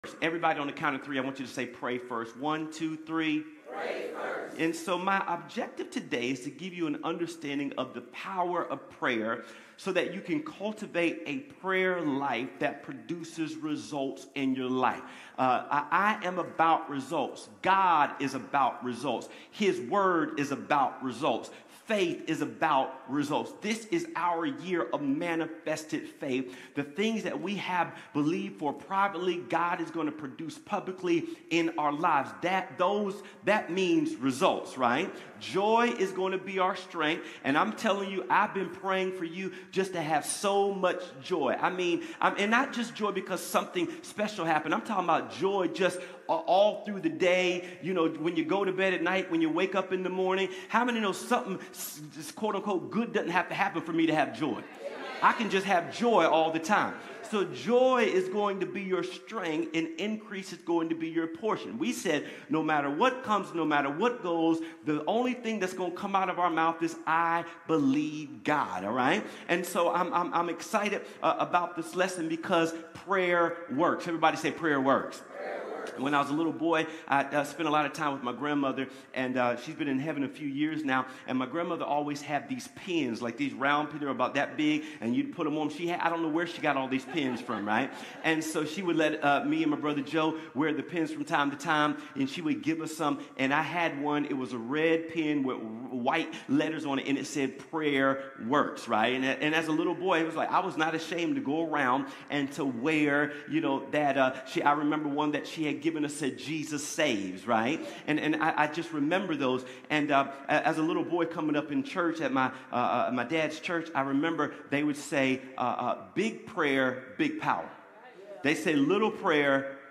Connect Groups Events Watch Church Online Sermons Give Pray First January 11, 2026